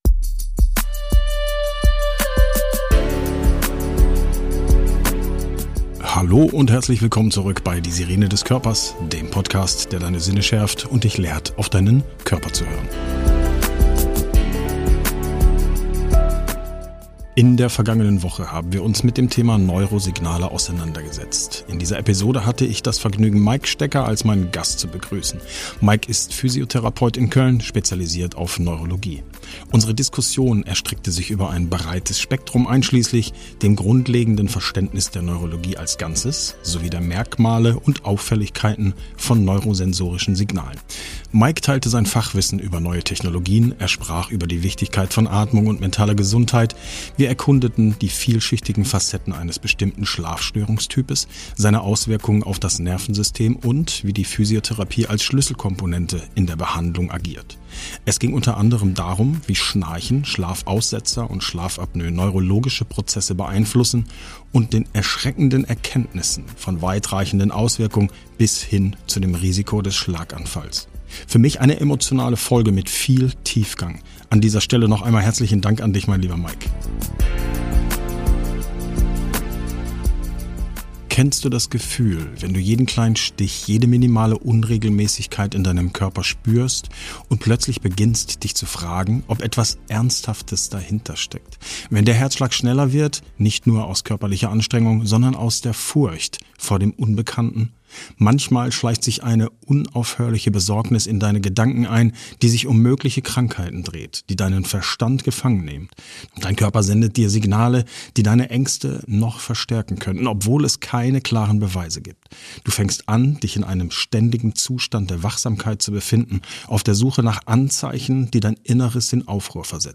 Beschreibung vor 2 Jahren In dieser Folge von "Die Sirene des Körpers" widmen wir uns gemeinsam mit einem Gast der Hypochondrie, einer oft verkannten seelischen Gesundheitsstörung. Unser Gast erzählt offen von seinem eigenen Weg mit der Hypochondrie.